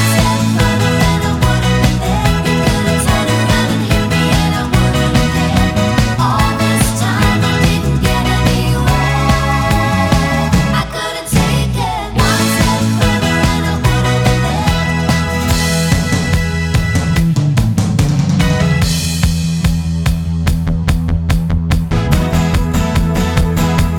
no Backing Vocals Pop (1980s) 3:01 Buy £1.50